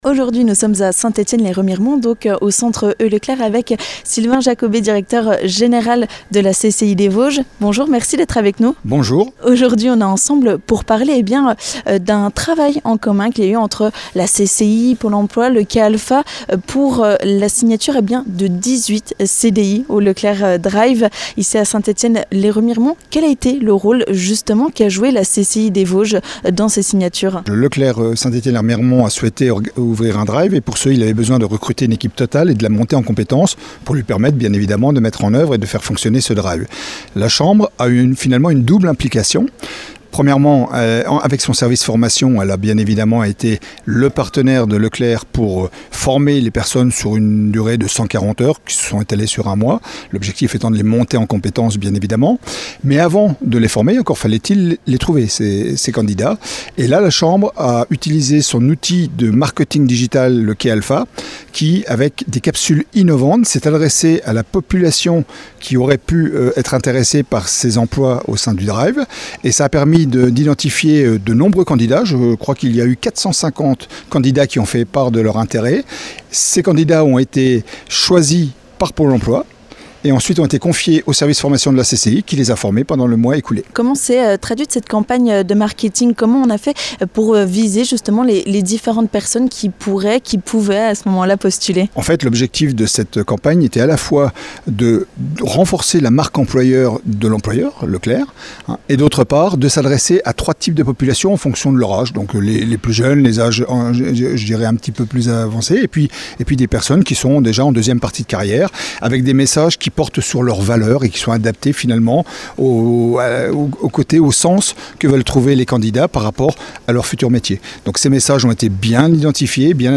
vous donne toutes les explications dans cette interview!